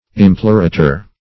Search Result for " implorator" : The Collaborative International Dictionary of English v.0.48: Implorator \Im"plo*ra`tor\, n. One who implores.